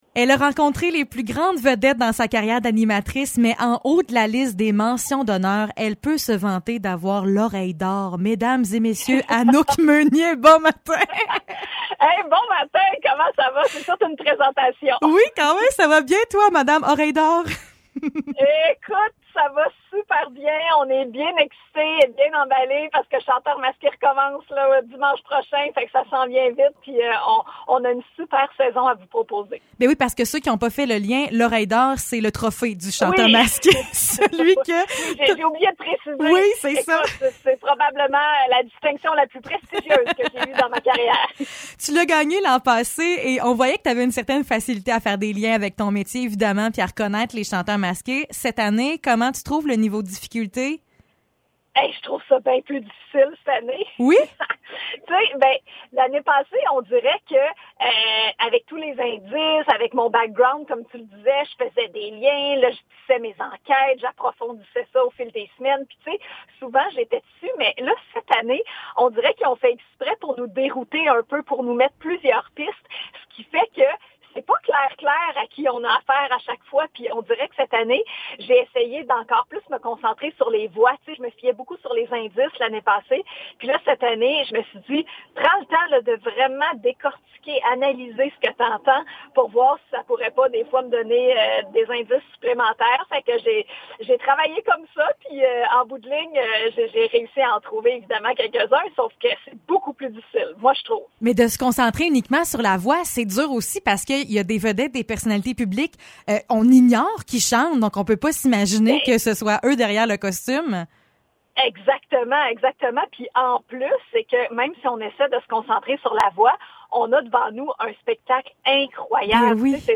Entrevue avec Anouk Meunier